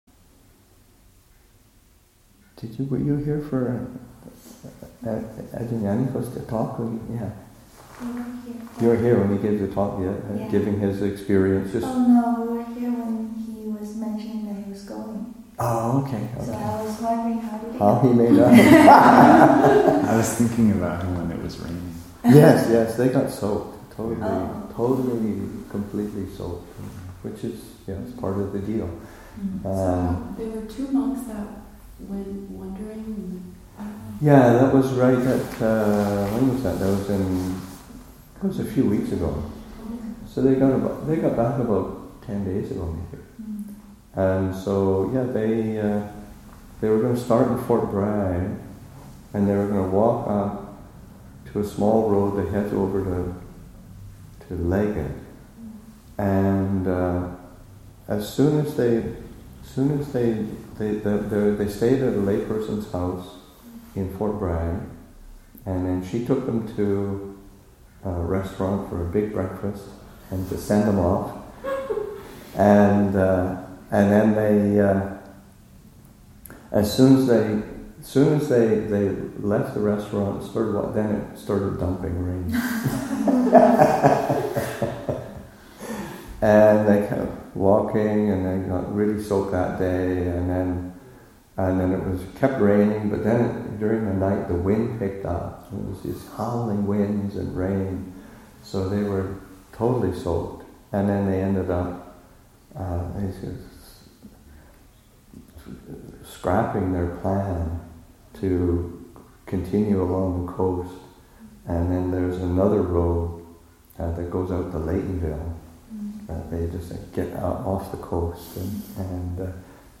Q&A session, Nov. 18, 2014